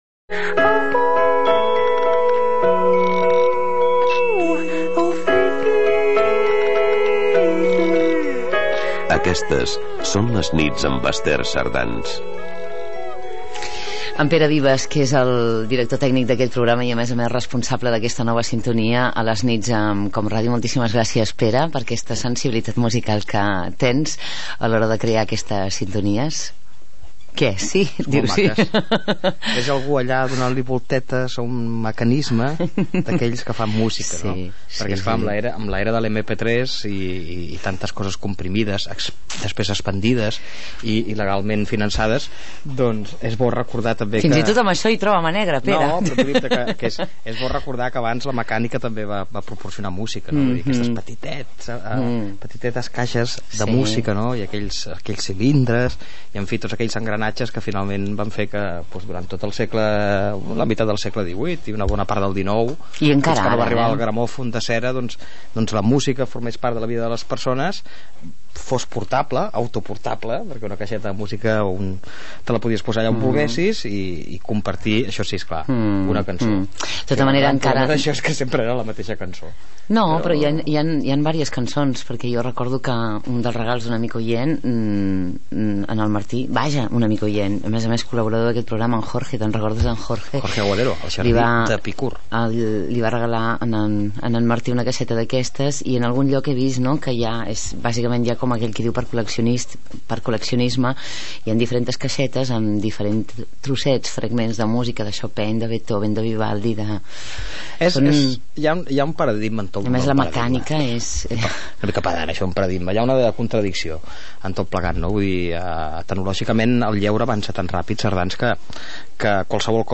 Indicatiu del programa, comentari sobre la música del programa, el format de so mp3 i la seva vigència, correu d'un oïdor sobre els partits polítics catalans
Entreteniment
FM